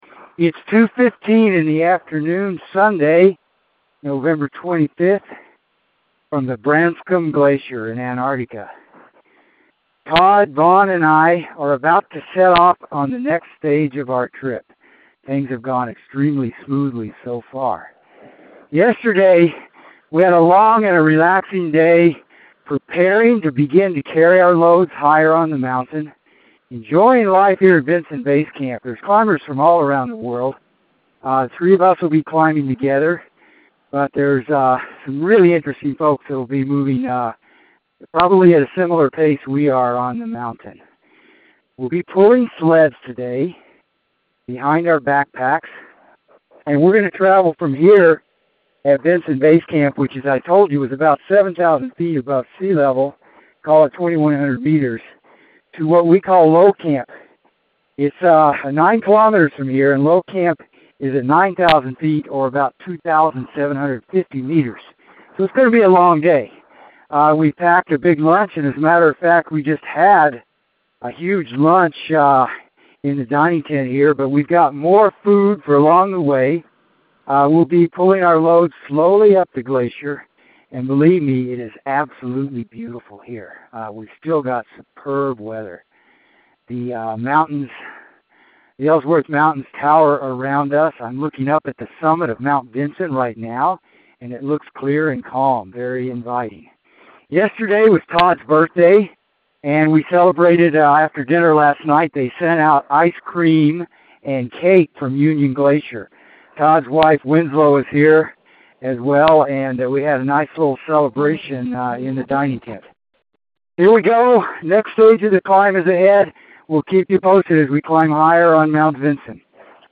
Expedition Dispatch